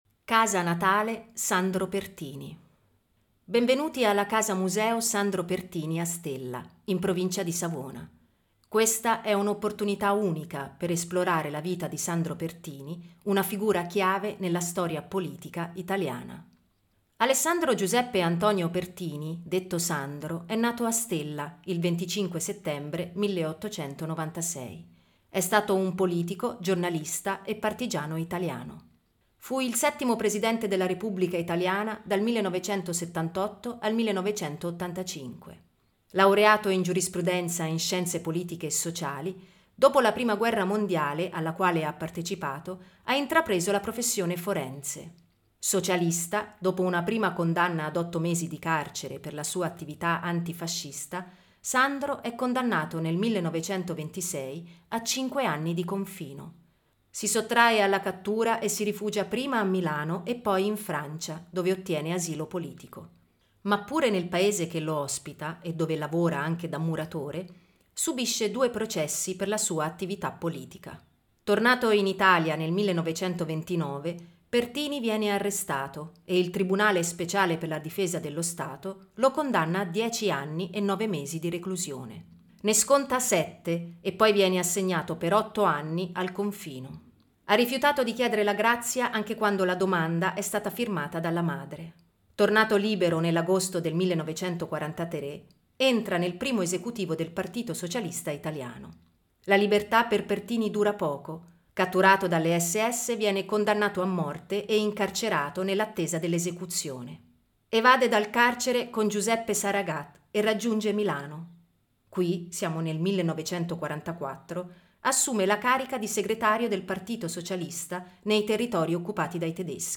audioguida-stella-museo-pertini.mp3